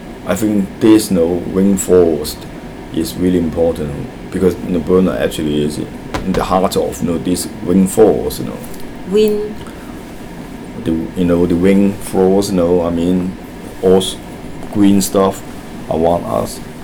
S1 = Taiwanese female S2 = Hong Kong male Context: S2 is talking about why he find it more comfortable to live in Brunei than his native Hong Kong.
Intended Words : this , rain forest , heart Heard as : days , wind forced , hot Discussion : S2 has [w] at the start of rain , and it is not clear that forest has two syllables.
S2 then tries to explain it, and in fact in his attempted explanatation, he does have a clearer [r] at the start of rain ; but even with this explanation, S1 was unable to transcribe rain forest correctly.